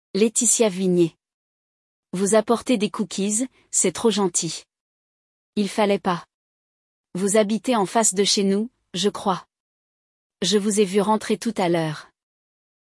Acompanhe esta conversa entre duas vizinhas enquanto aprende sobre o uso dos verbos vouloir e apporter!